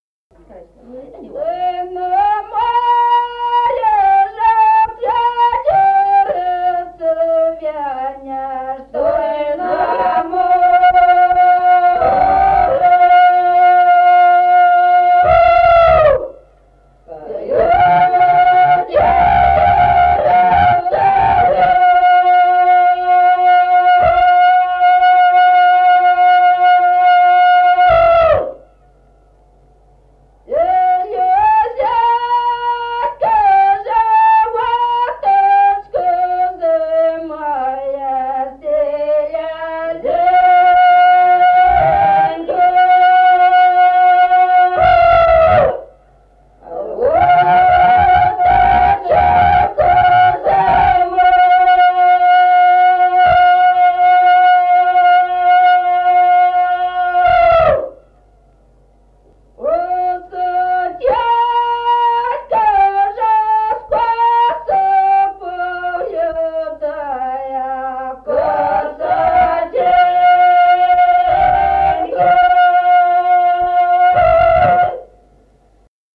Песни села Остроглядово. Ой, на море же клён-деревце (масленичная) И 0055-05